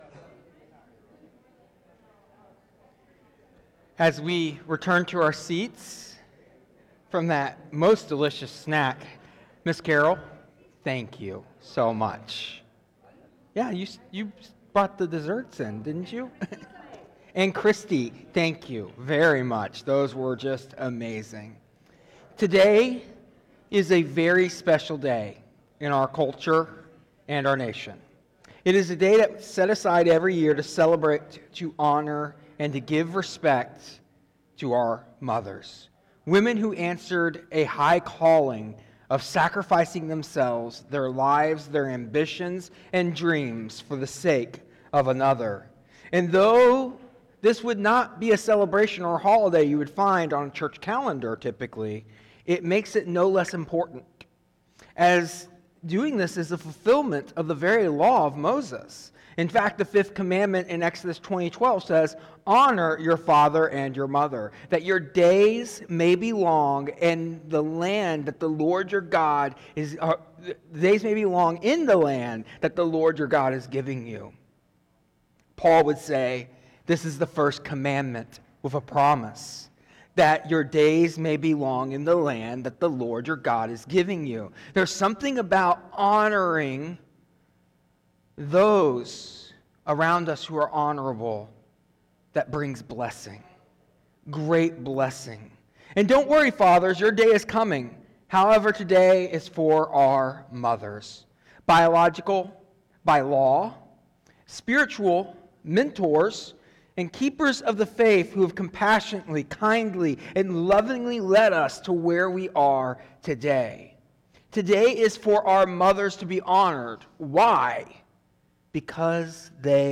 Today’s message emphasizes the significance of honoring mothers on this special day, reflecting on their role as image bearers of God who embody His qualities through care, guidance, sacrificial love, prayer, and faithfulness. It illustrates that motherhood transcends biology, highlighting figures like Naomi who exemplify this fact. The sermon further illustrates how godly mothers are called to pray relentlessly for their children and live faithfully, impacting their lives and shaping their destinies.